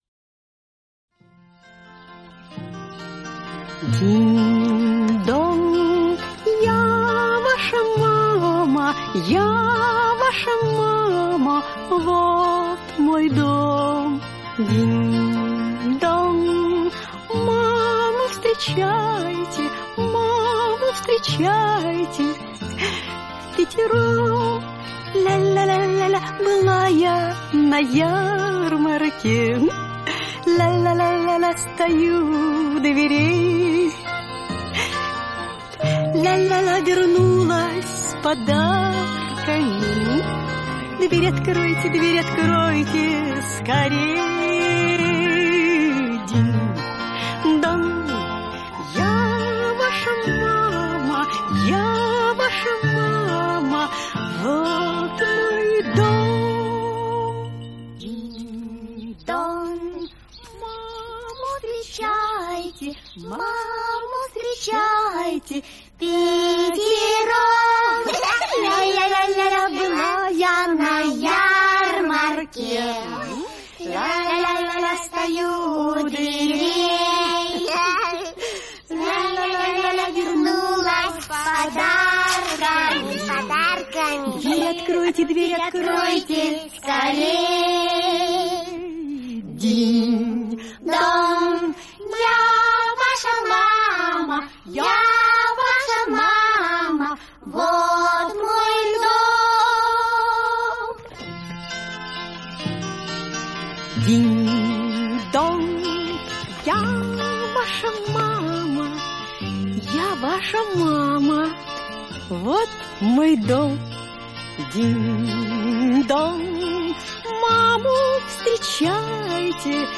песня из советского мультфильма